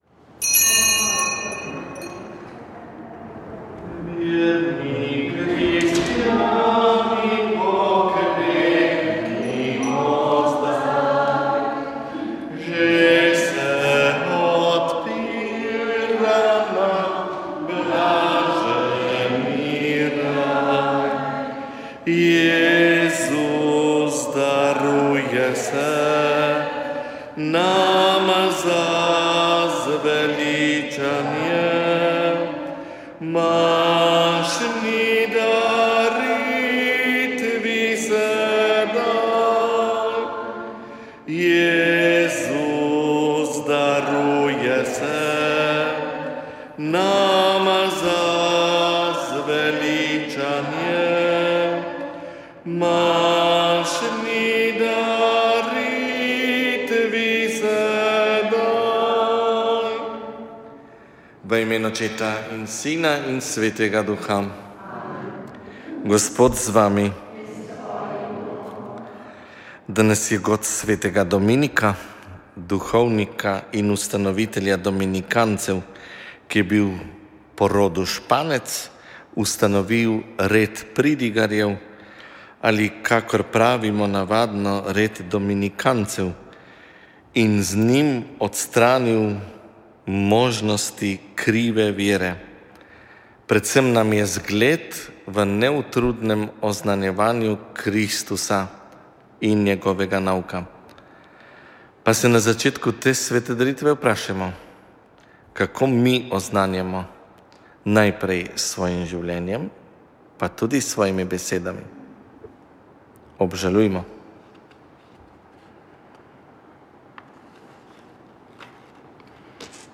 Sveta maša
Sv. maša iz cerkve sv. Marka na Markovcu v Kopru 8. 8.